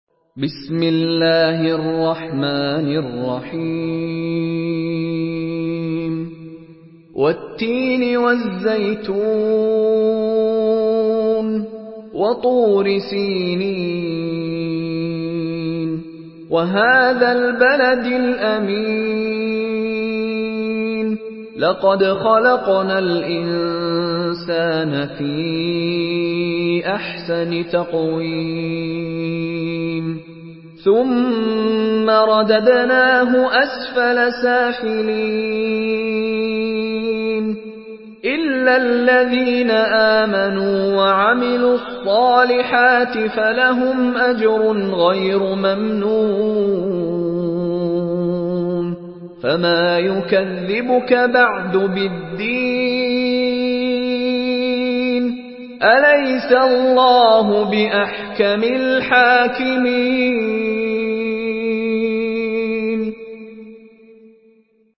Surah التين MP3 by مشاري راشد العفاسي in حفص عن عاصم narration.
مرتل